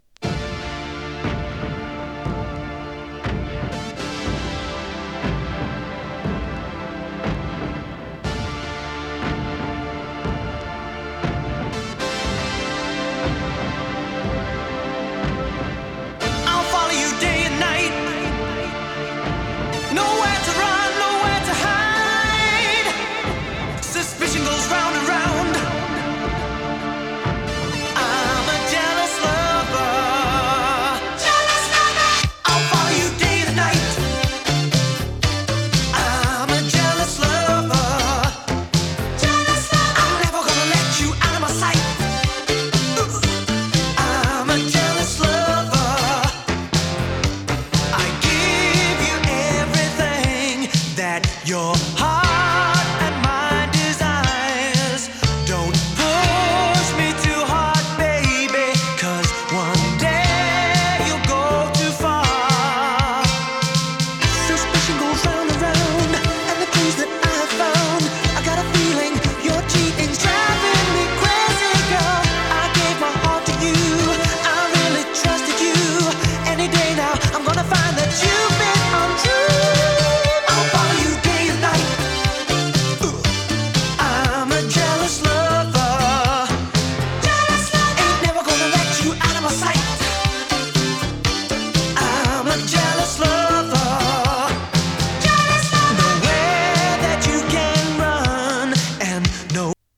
胸騒ぎを掻き立てるアーバン・エレクトロ・ファンク！